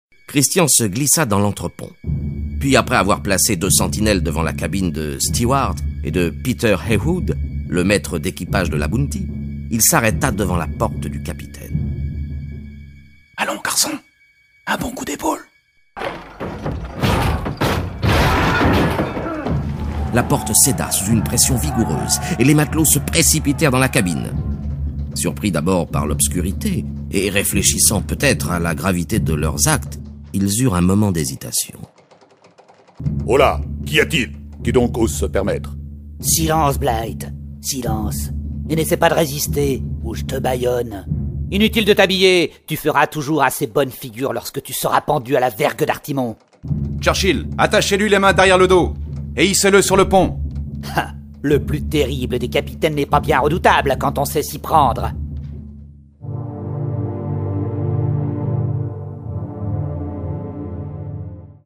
Vous le découvrirez en écoutant cette adaptation sonore des "révoltés du Bounty".